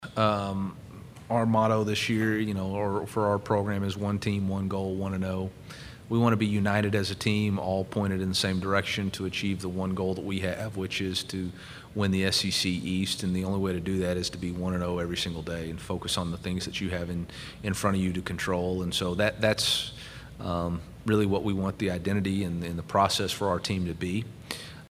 Missouri head coach Eliah Drinkwitz speaks during an NCAA college football news conference at the Southeastern Conference Media Days, Monday, July 18, 2022, in Atlanta.